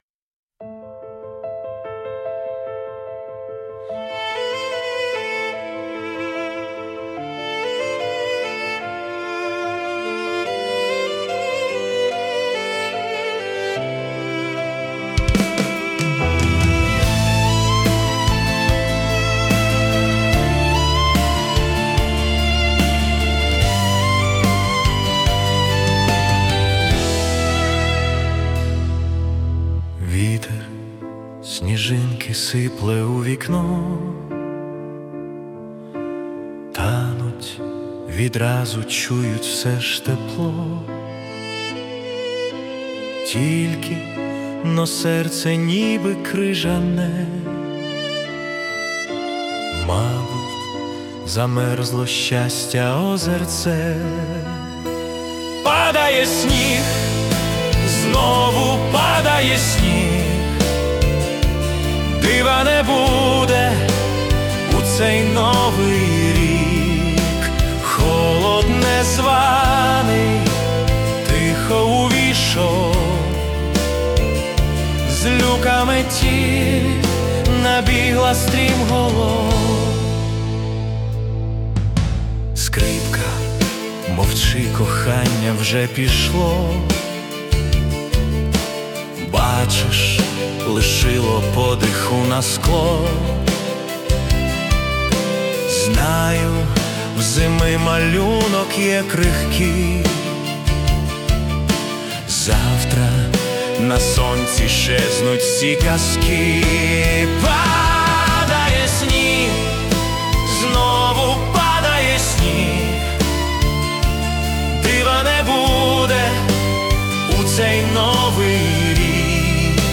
Музична композиція створена за допомогою SUNO AI
СТИЛЬОВІ ЖАНРИ: Ліричний
Гарні слова, вдалий музичний супровід !!